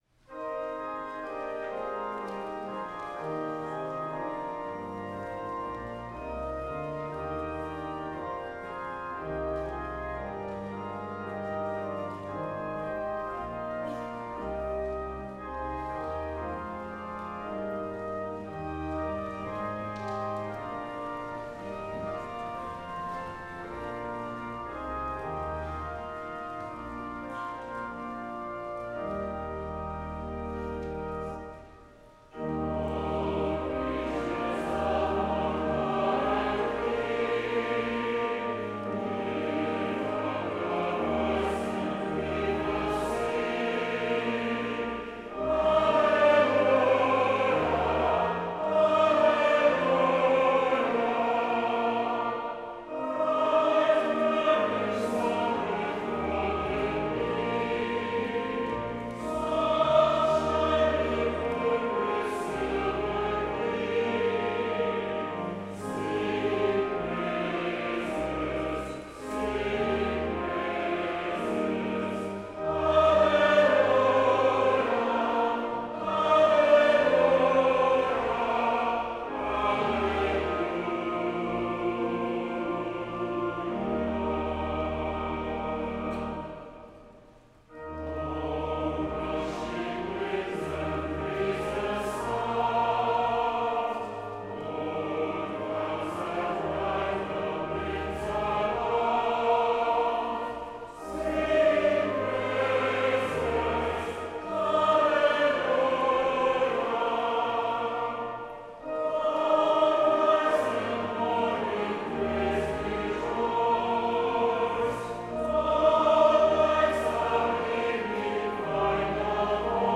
OPENING HYMN  All Creatures of Our God and King               Music
Fairlawn Avenue Senior Choir and congregation